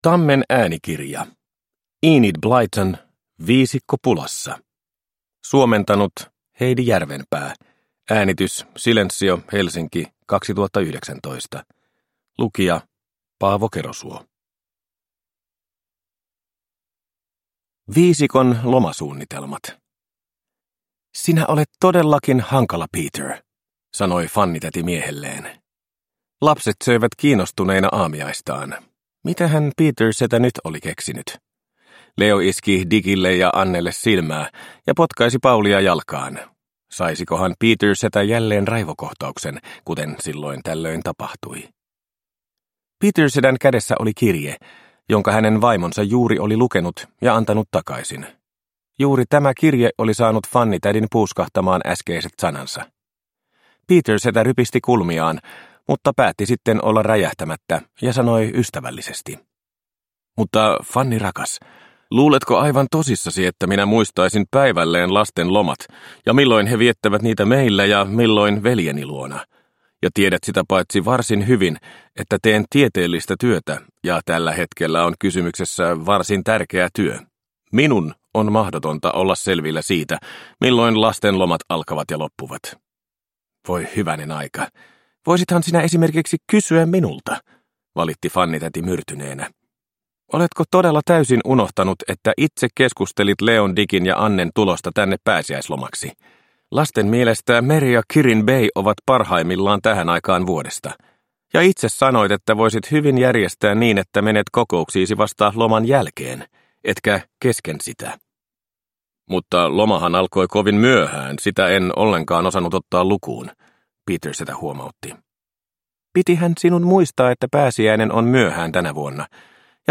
Viisikko pulassa – Ljudbok – Laddas ner